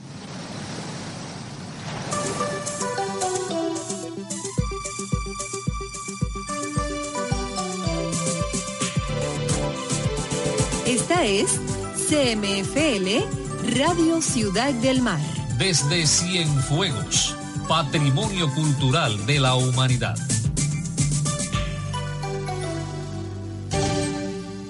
Audio clips with transcriptions of hourly radio station identifications.